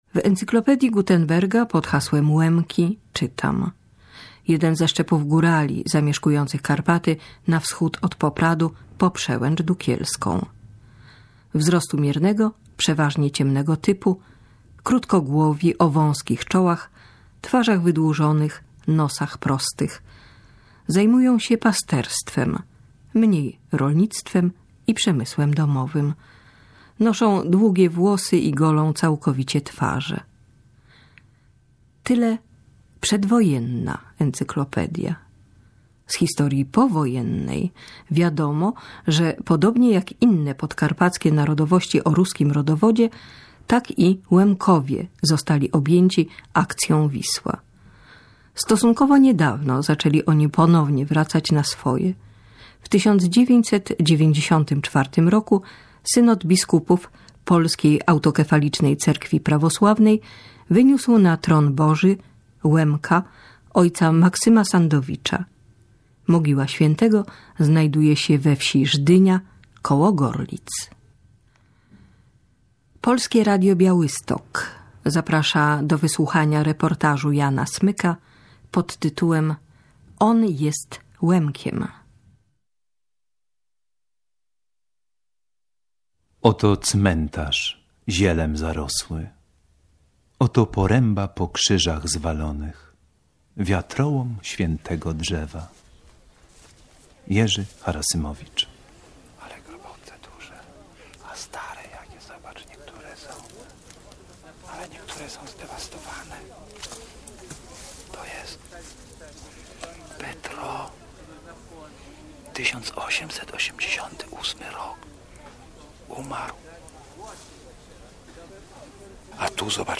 Miejsce nagrania: Gorlice, Żdynia